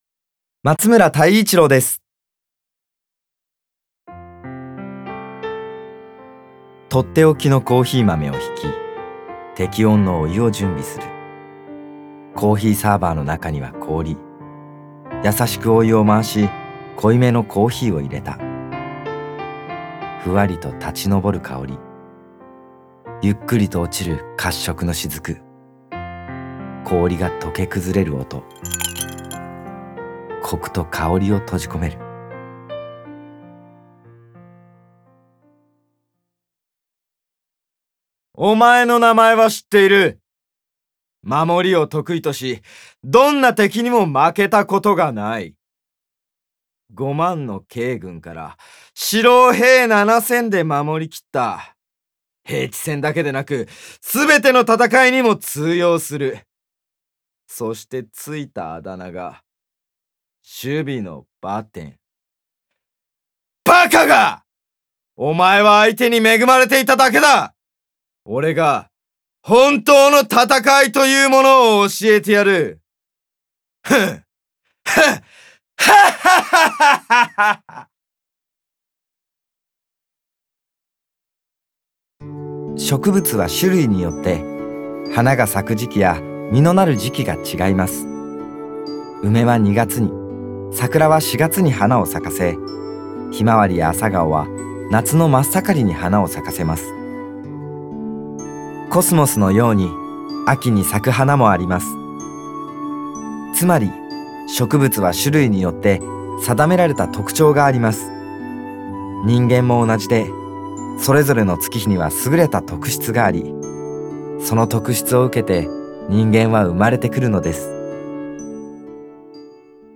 • 声優
VOICE SAMPLE